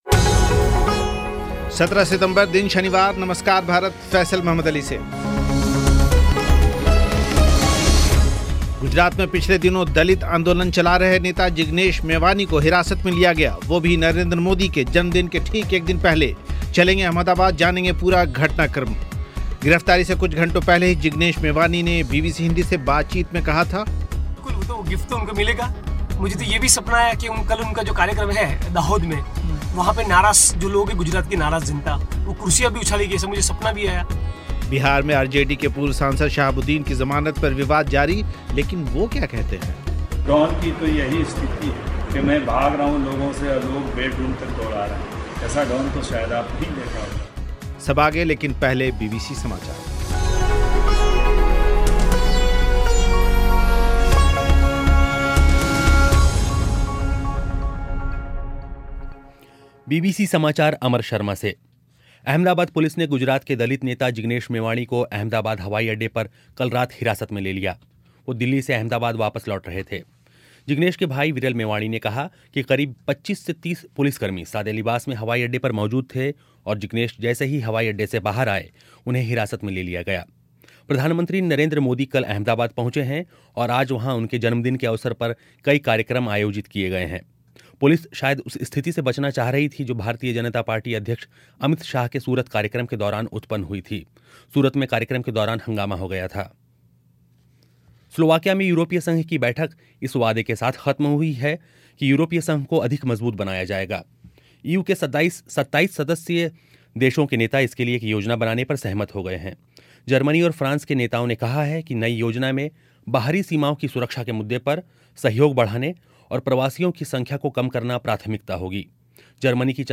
एक इंटरव्यू